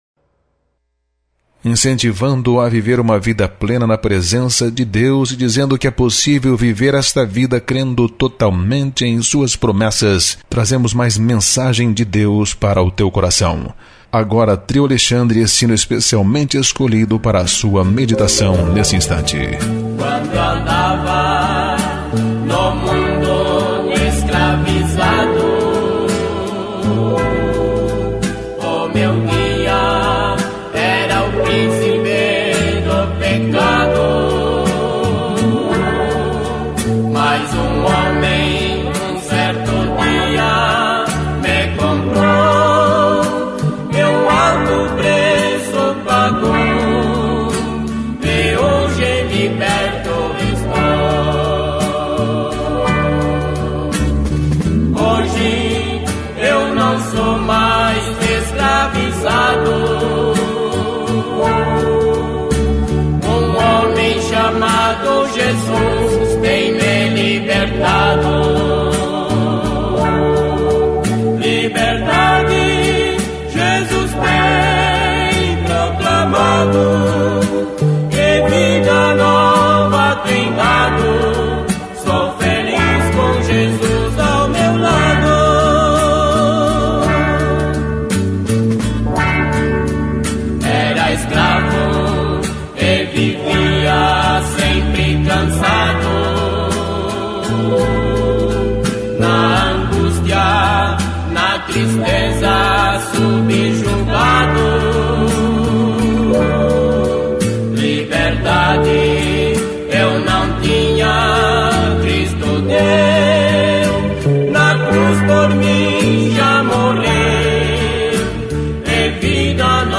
Programa Musical Evangélico com louvores que edificam e alegram a nossa alma